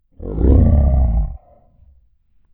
Add dino roar sound files
dino-roar-01.wav